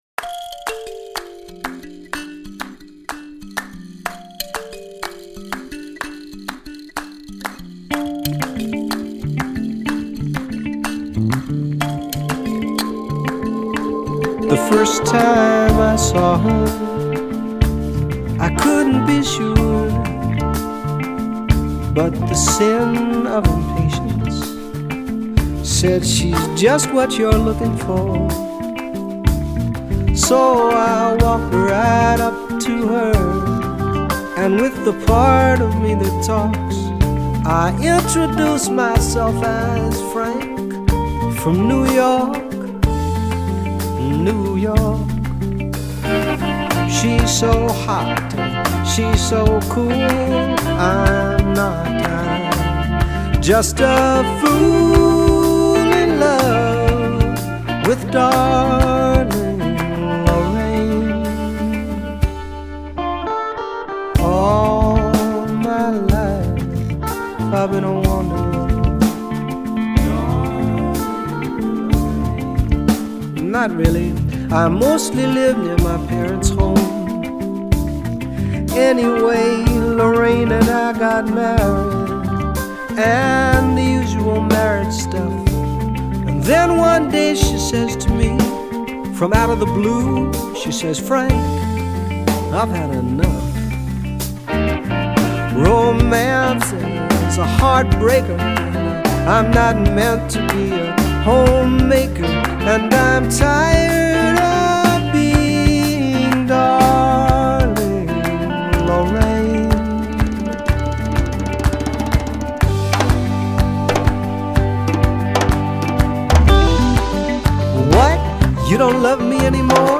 It has a lush groove and a great conversational tone…